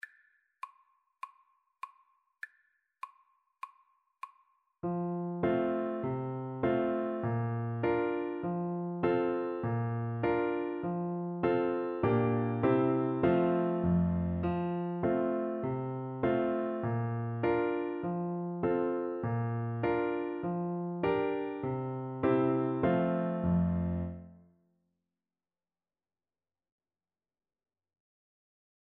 Childrens
G major (Sounding Pitch) (View more G major Music for Oboe )
4/4 (View more 4/4 Music)
Moderato
Beginners Level: Recommended for Beginners